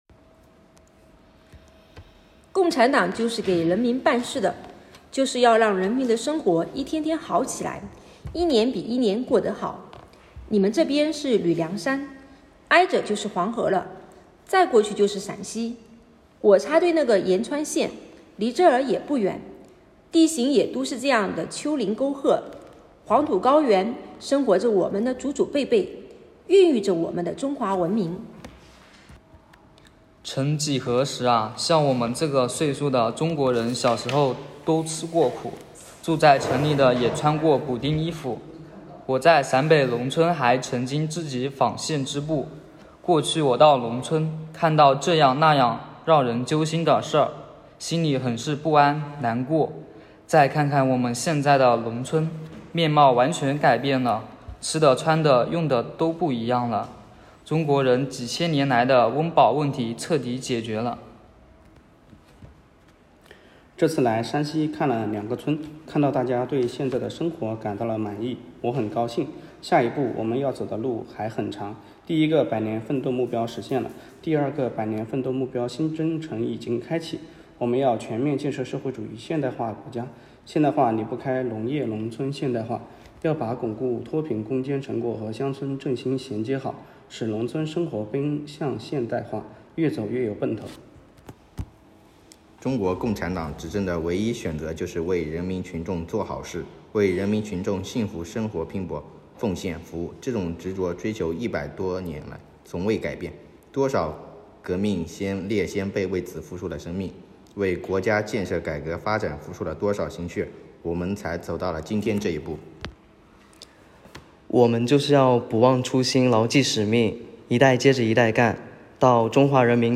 喜迎二十大 诵读新时代①丨师生领学《习近平谈治国理政》第四卷